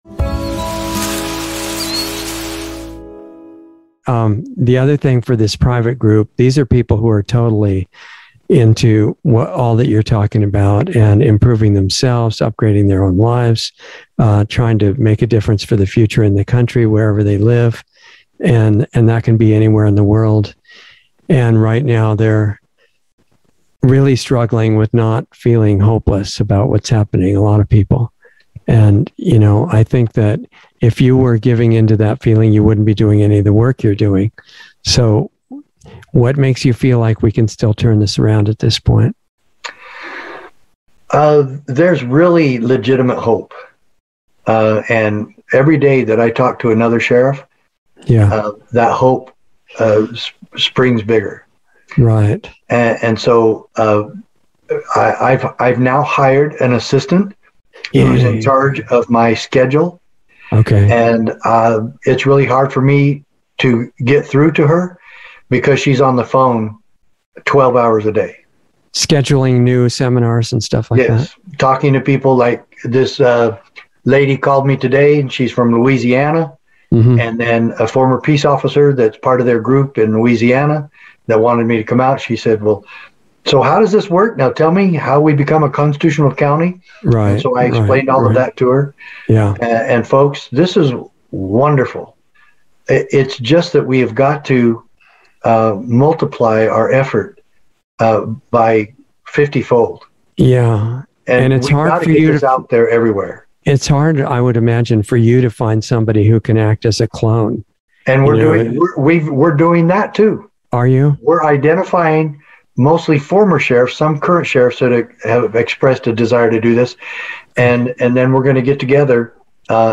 Planetary Healing Club - Sheriff Richard Mack - Insider Interview 9/9/21